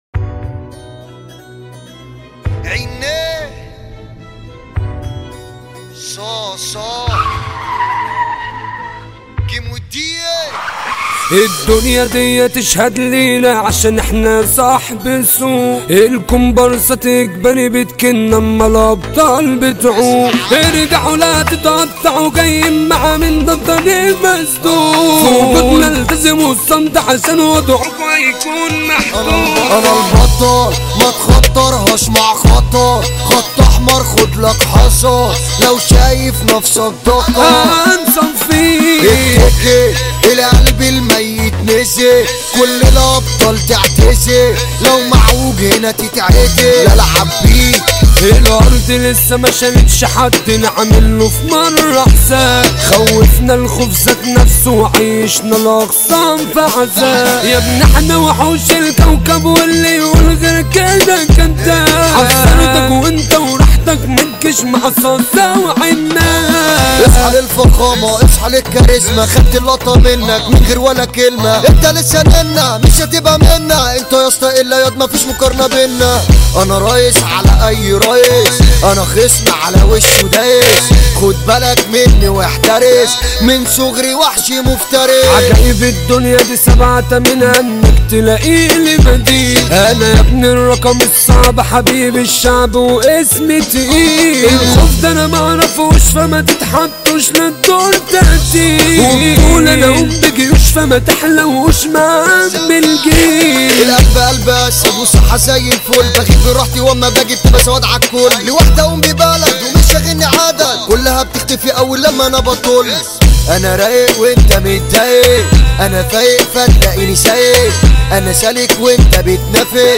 اغانى مهرجانات